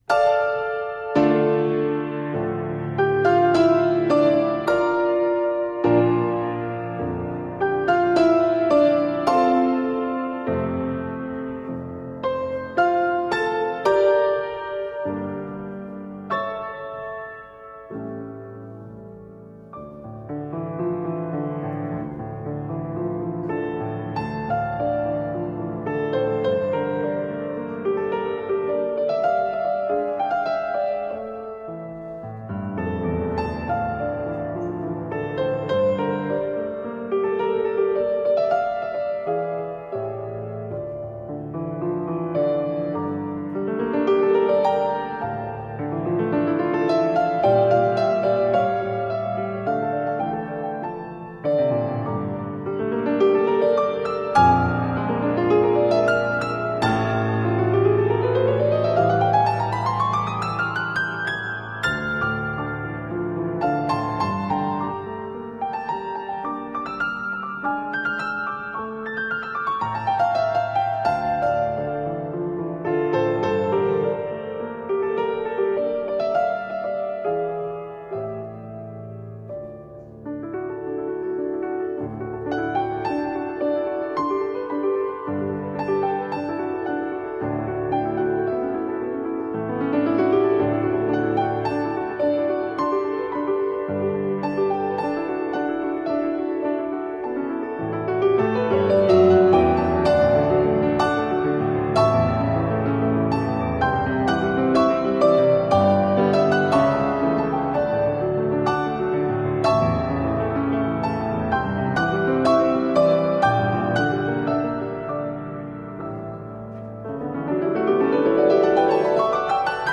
风格多样,效果炸裂,太喜欢啦